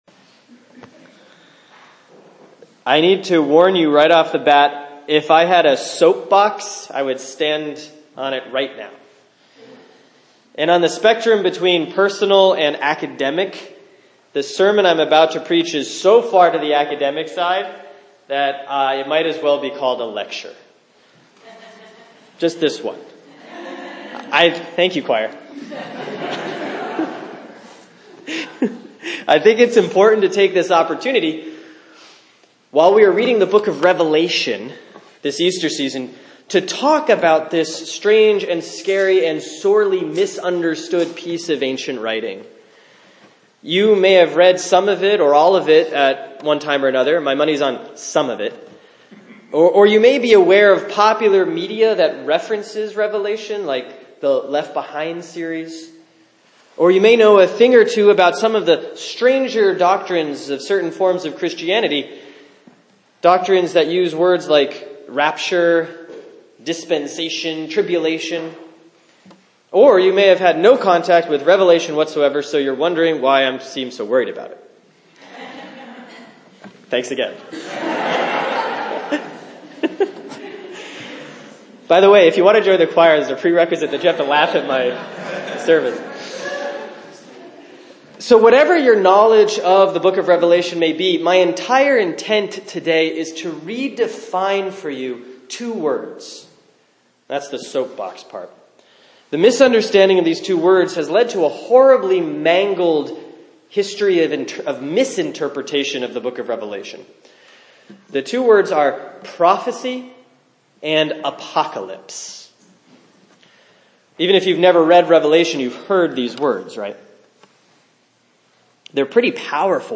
Sermon for Sunday, April 24, 2016 || Easter 5C || Revelation 21:1-6